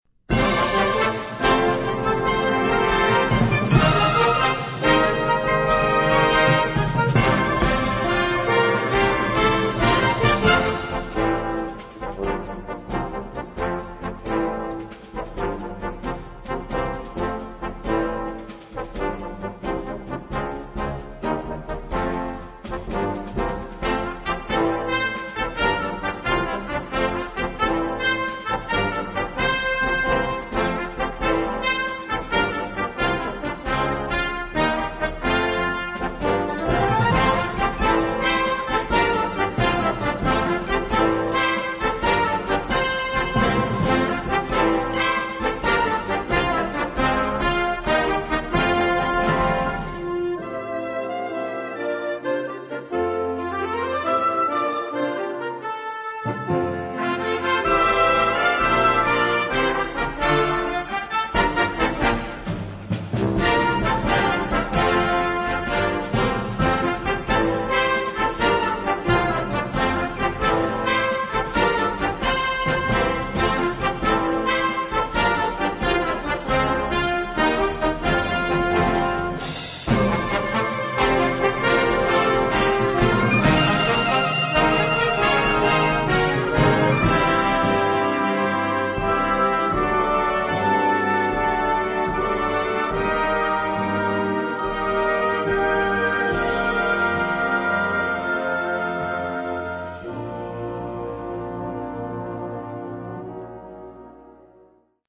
exciting rhythms, bold harmonies and widespread mixed meters
Partitions pour orchestre d'harmonie.